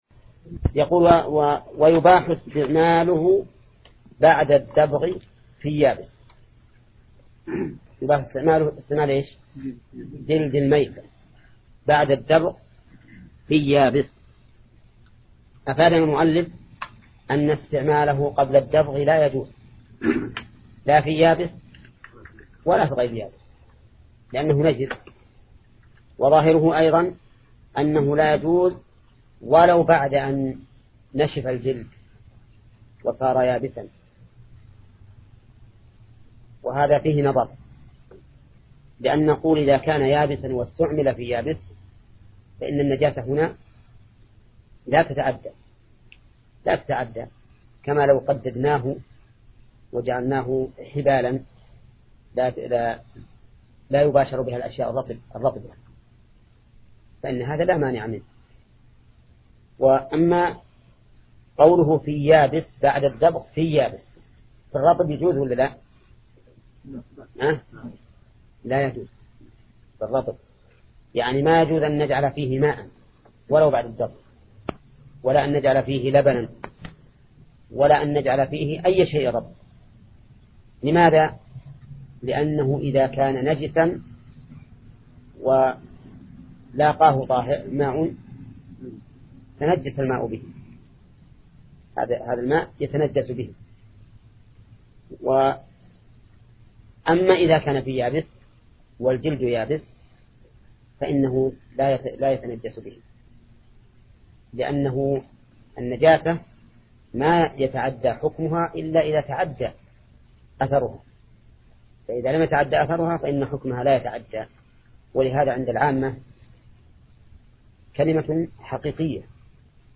درس : (5) : تتمة باب الآنية / باب الإستنجاء